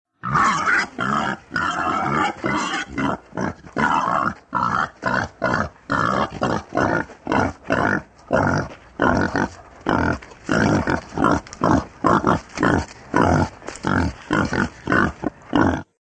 Звуки кабанов
Дикий кабан хрюкает